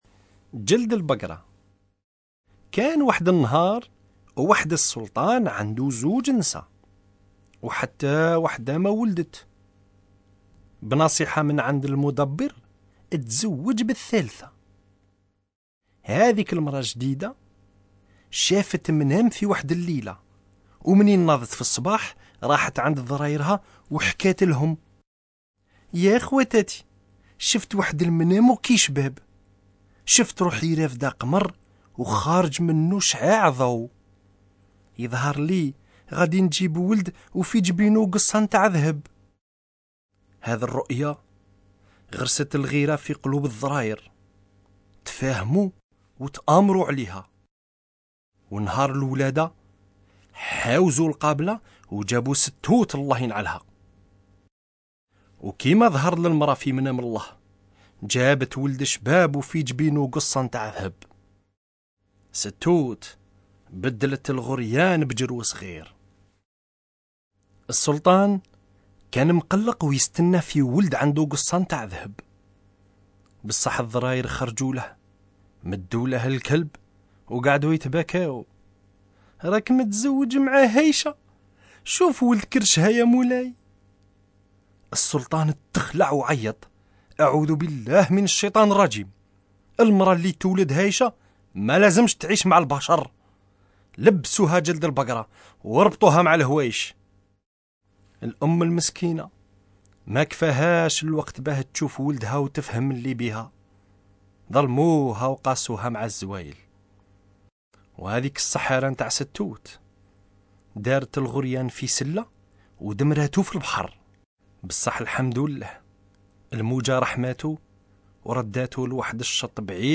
Écoutez les contes populaires d'Algérie, entre humour, justice et traditions orales, racontés en français et en arabe algéien.